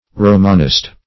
Romanist \Ro"man*ist\, n.